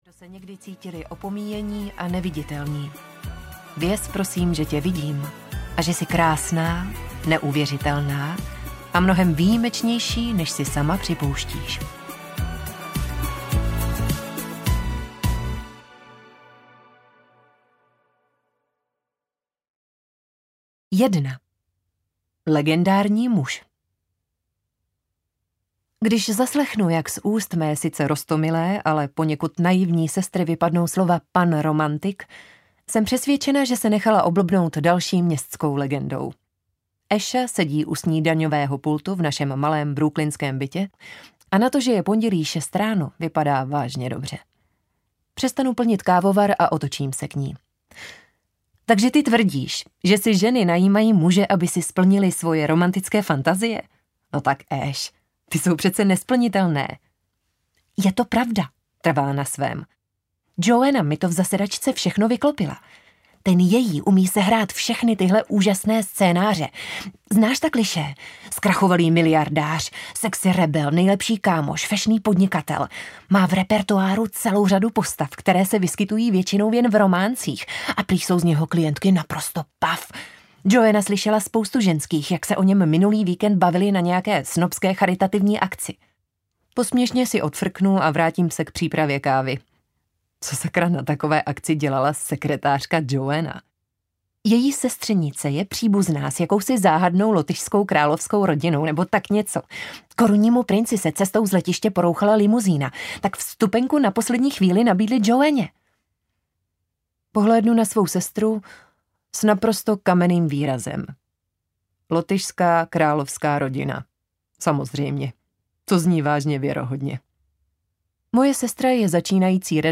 Pan Romantik audiokniha
Ukázka z knihy